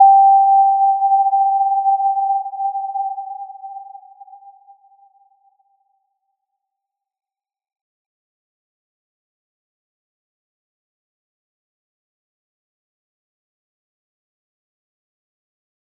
Little-Pluck-G5-p.wav